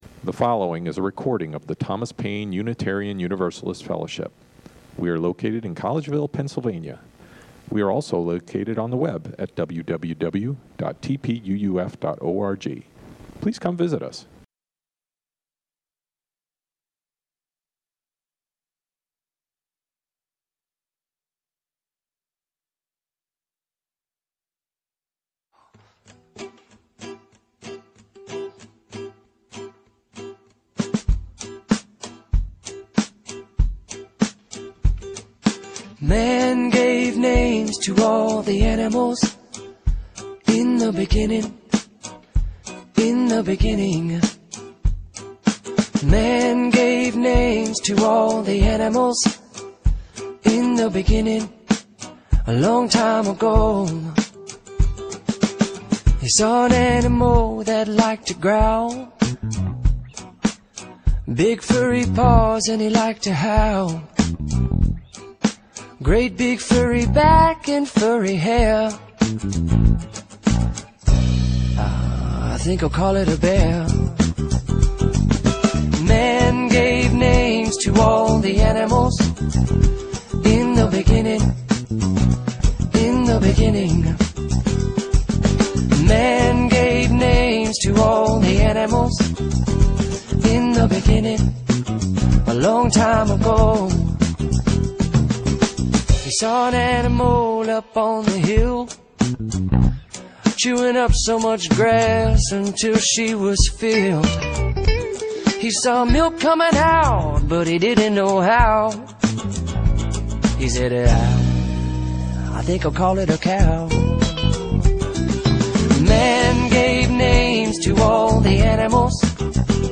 pet-blessing-service-2